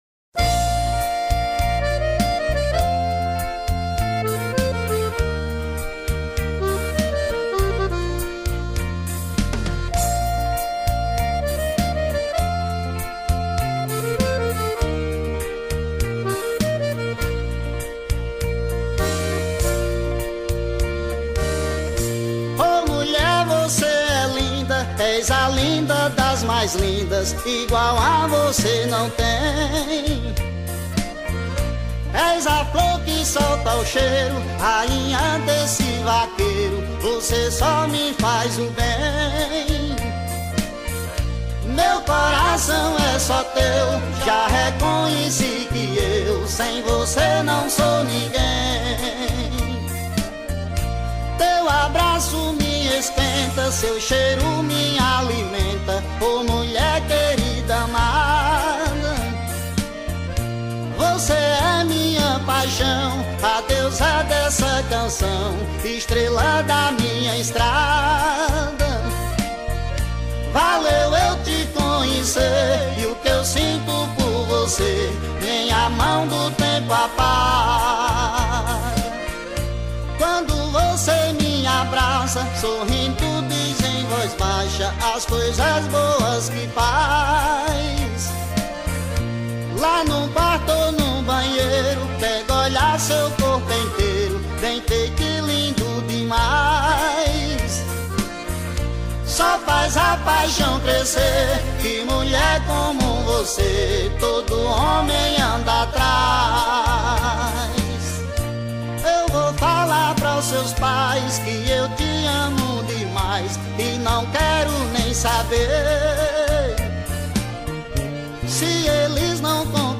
2024-02-21 06:49:58 Gênero: Forró Views